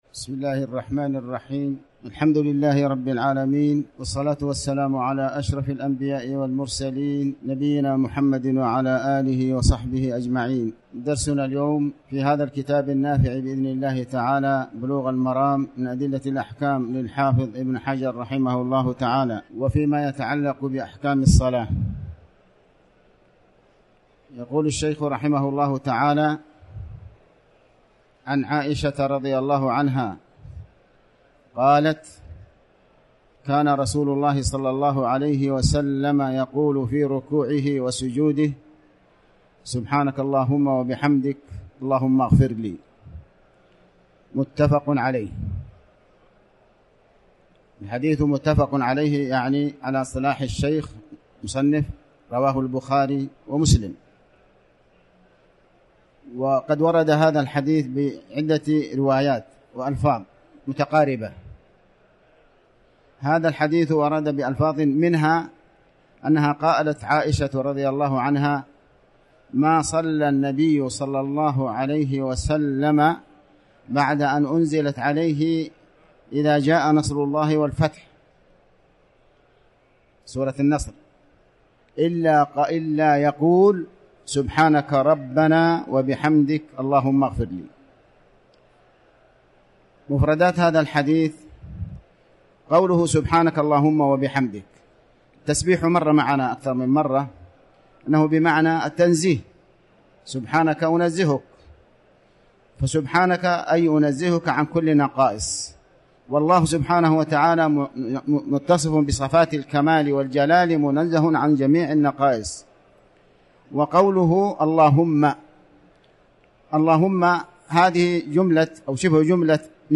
تاريخ النشر ٢٤ محرم ١٤٤٠ هـ المكان: المسجد الحرام الشيخ